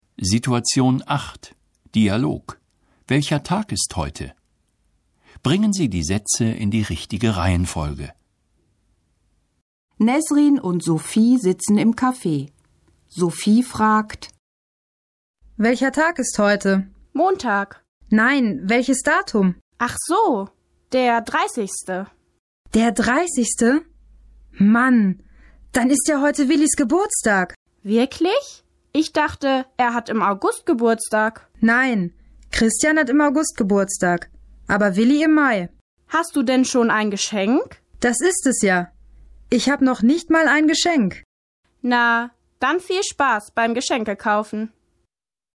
Situation 8 – Dialog: Welcher Tag ist heute? (729.0K)